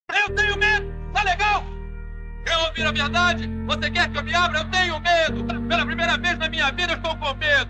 ru tenho medo rocky Meme Sound Effect
Category: Movie Soundboard